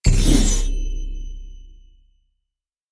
11310 gold vanish ding
bell ding gold metal ring shine ting treasure sound effect free sound royalty free Sound Effects